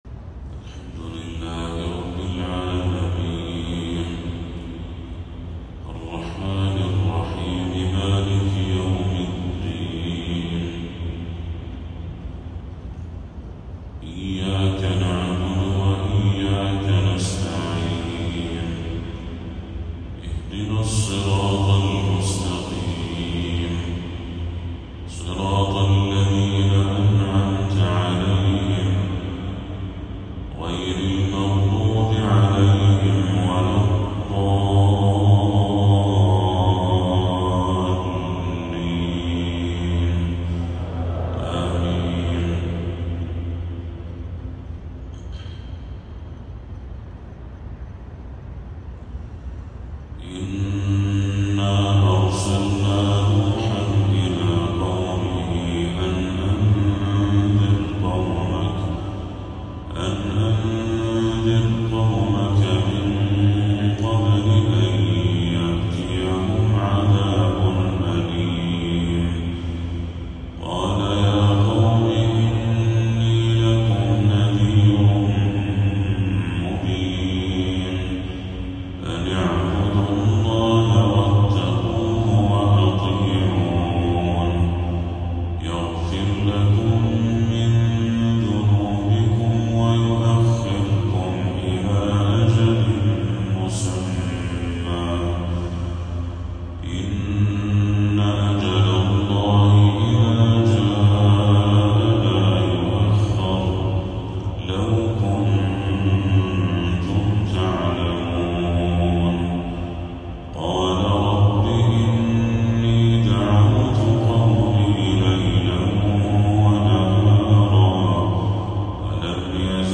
تلاوة هادئة لسورة نوح كاملة للشيخ بدر التركي | فجر 11 ربيع الأول 1446هـ > 1446هـ > تلاوات الشيخ بدر التركي > المزيد - تلاوات الحرمين